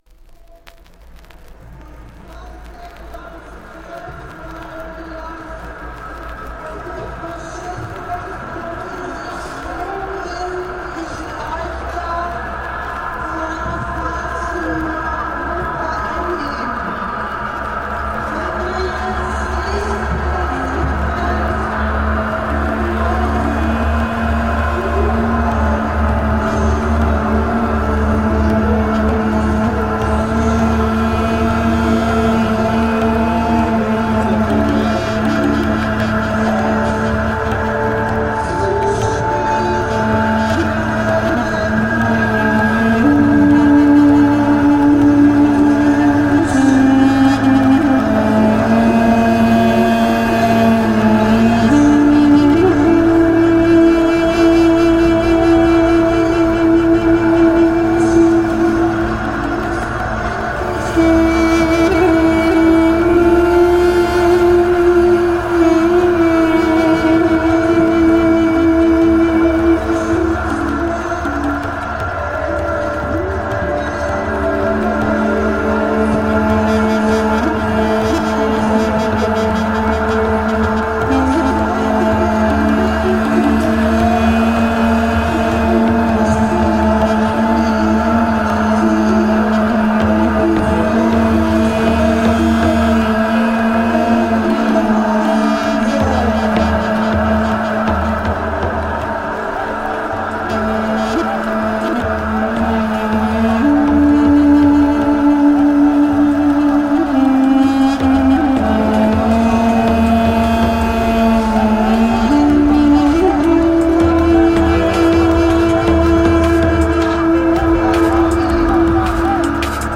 Hamburg anti-ISIS protest reimagined